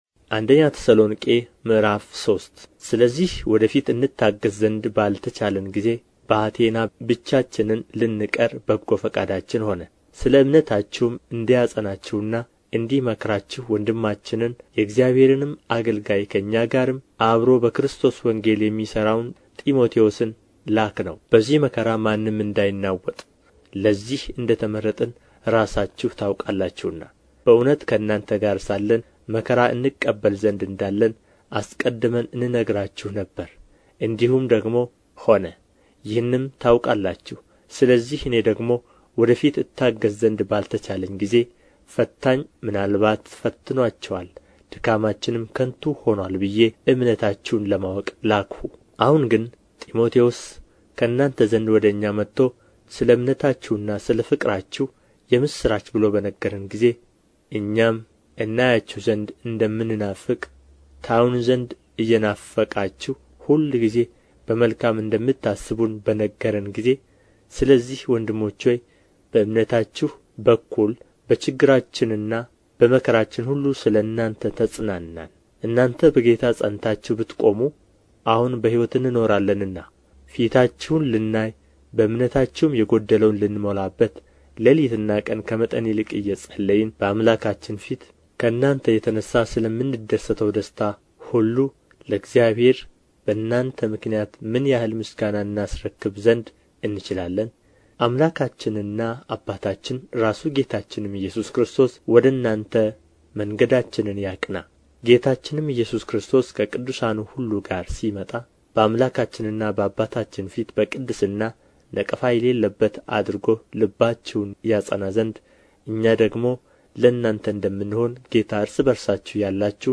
ምዕራፍ 3 ንባብ